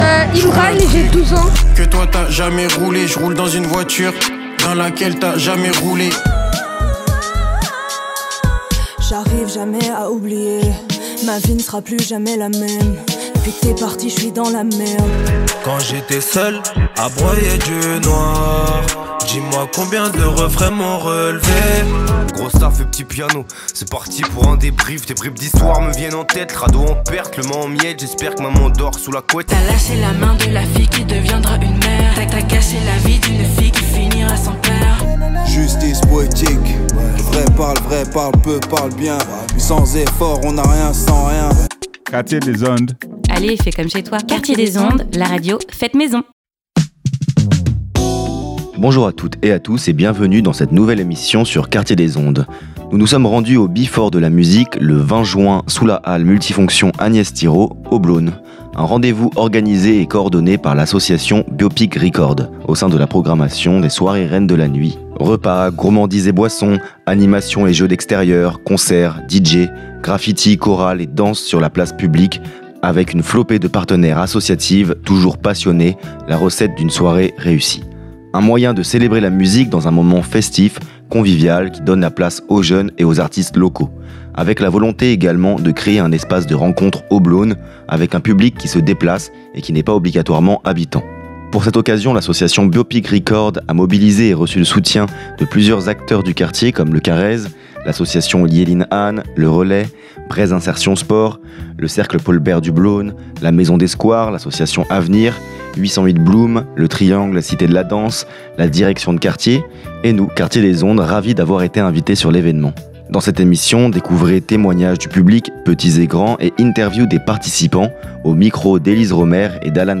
Le 20 juin se tenait le Before de la musique au Blosne.
Un beau rendez-vous organisé et coordonné par l’association Biopic Record sous la halle multifonctions Agnès Tirop au Blosne.